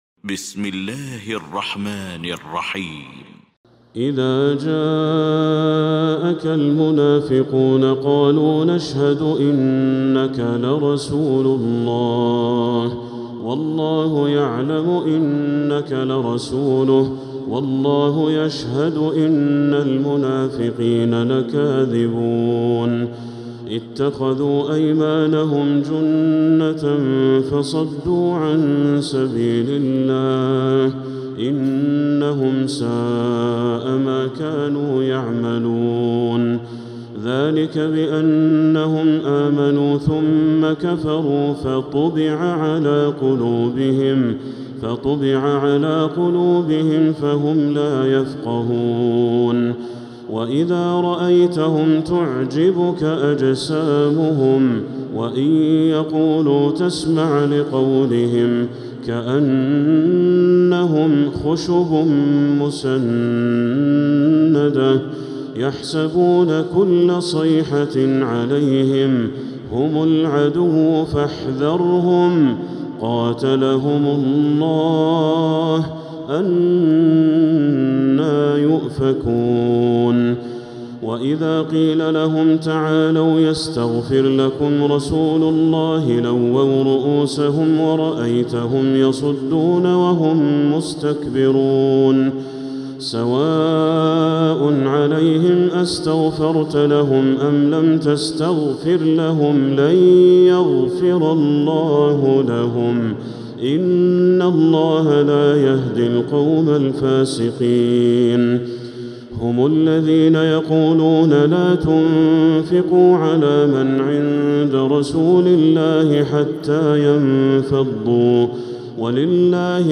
المكان: المسجد الحرام الشيخ: بدر التركي بدر التركي المنافقون